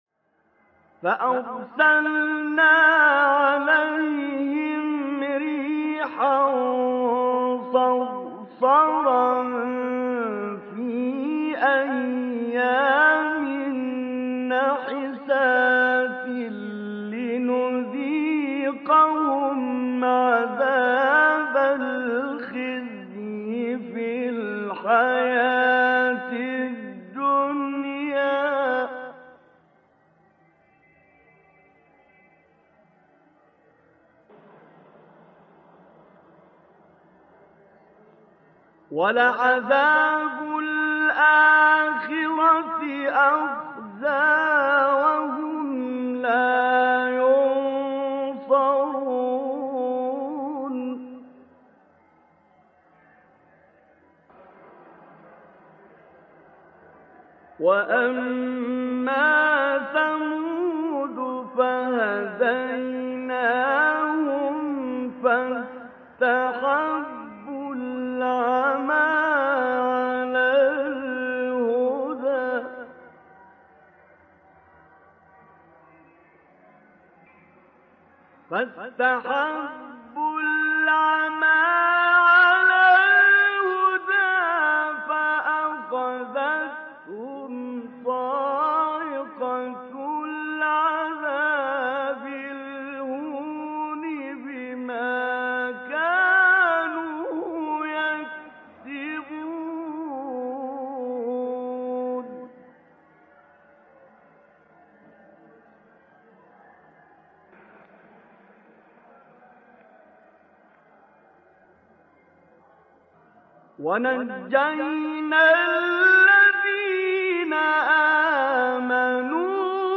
تلاوت بخشی از سوره فصلت با صدای استاد طنطاوی | نغمات قرآن | دانلود تلاوت قرآن